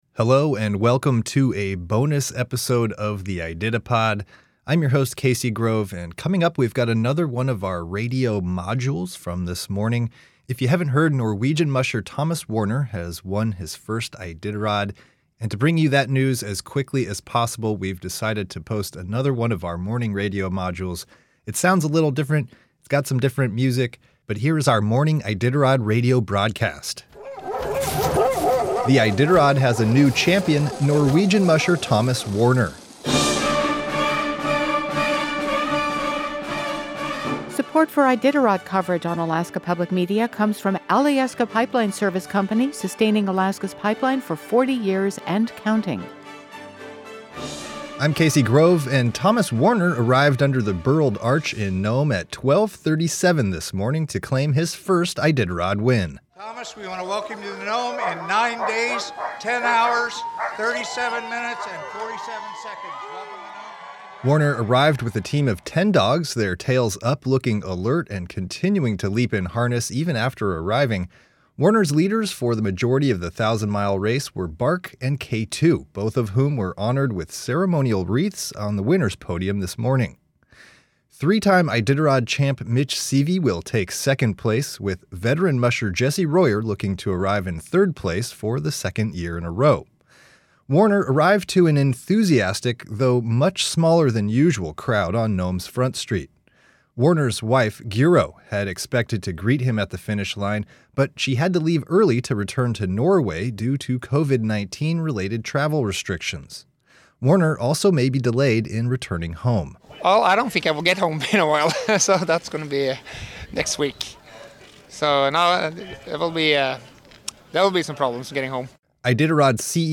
This is a quick report from our morning radio coverage of the Iditarod. It's called a radio module, and it details Norwegian musher Thomas Waerner winning the 2020 Iditarod Trail Sled Dog Race at 12:37 a.m. Wednesday, March 18.